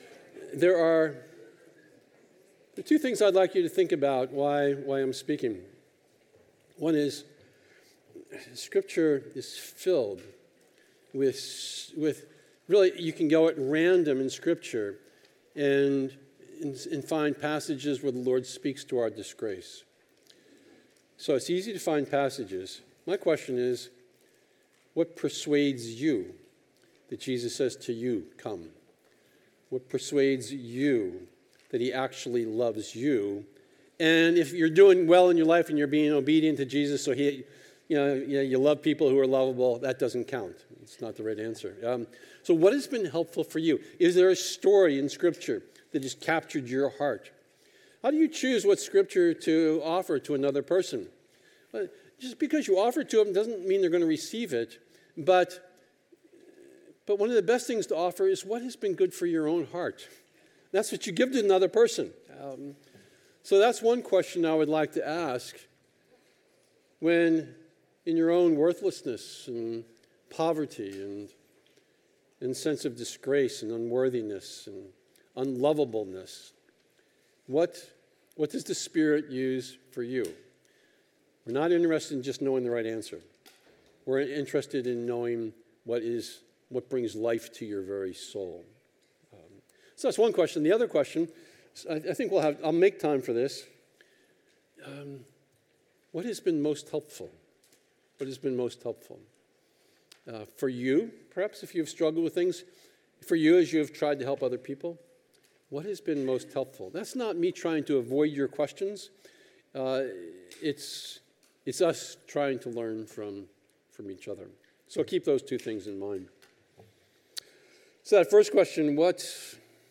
This is session from the Biblical Counseling Training Conference hosted by Faith Church in Lafayette, Indiana.